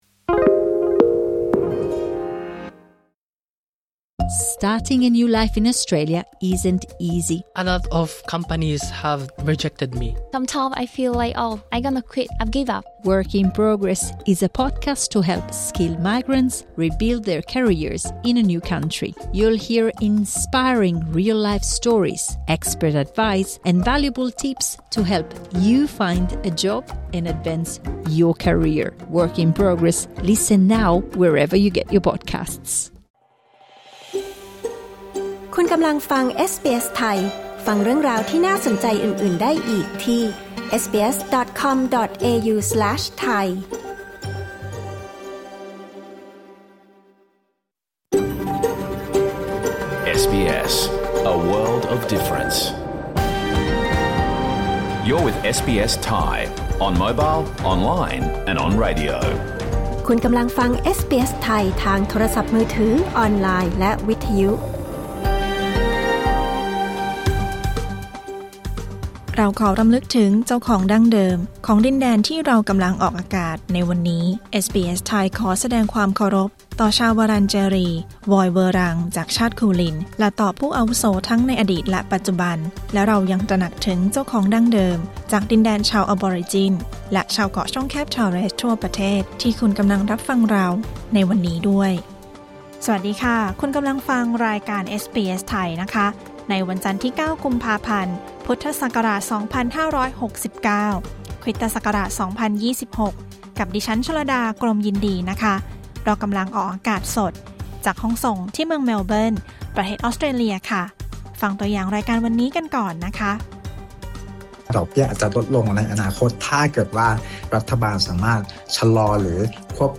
รายการสด 9 กุมภาพันธ์ 2569
ฟังสรุปข่าวรอบวัน เสียงสะท้อนจากเยาวชน ผู้ปกครอง และผู้เชี่ยวชาญ